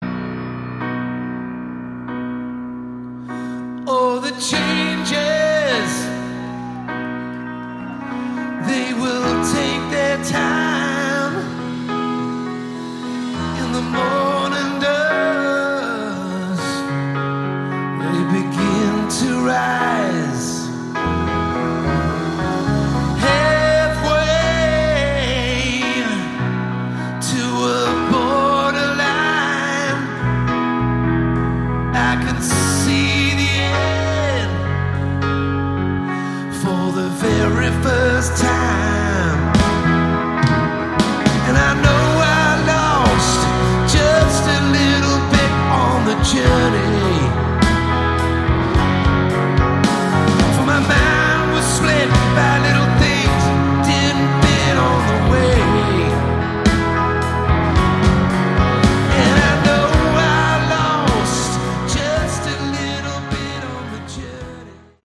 Category: Hard Rock
vocals, guitar, keyboards
drums
bass